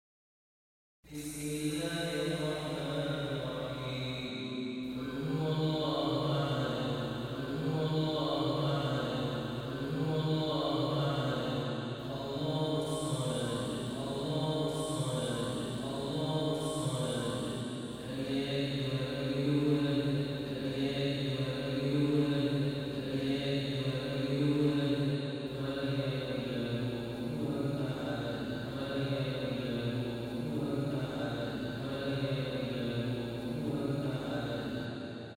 The Holy Quran recitation for Famous readers to listen and download